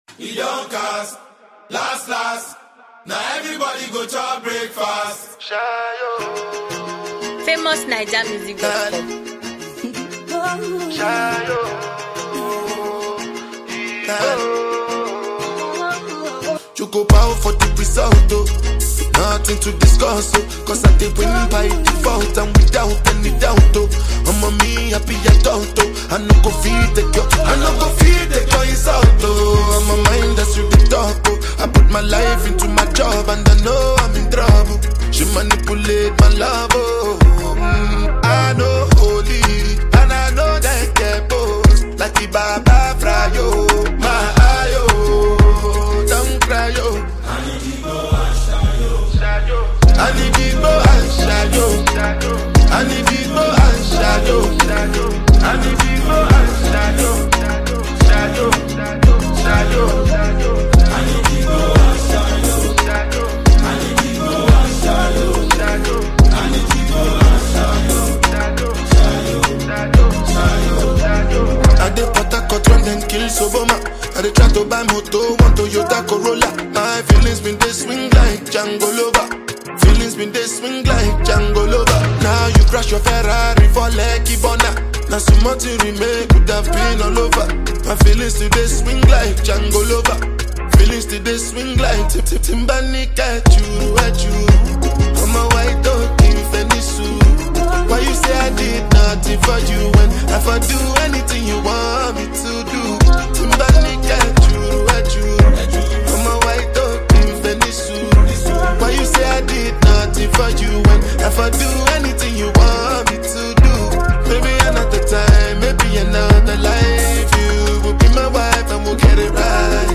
” presently has an instrumental accessible.